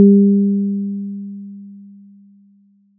The sound was generated right in all cases, which was an accurate wave experiment set sounding sort of like